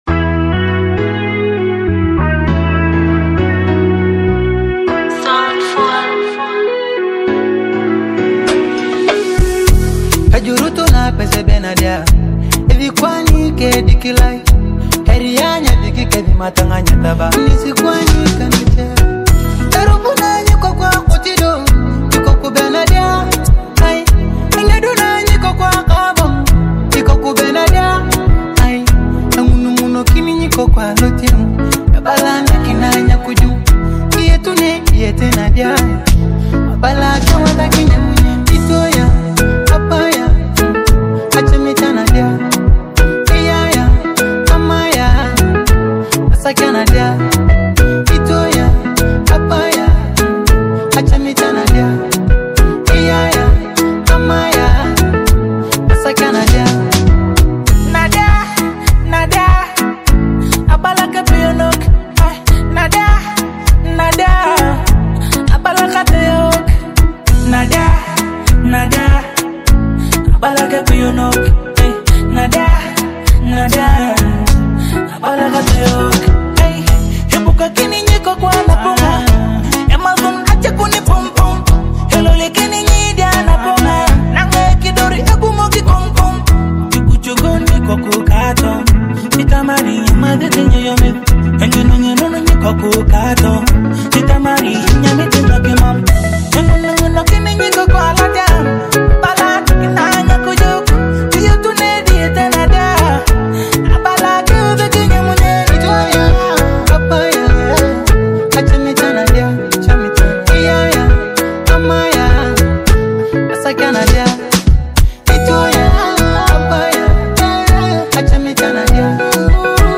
With its catchy hooks and irresistible beat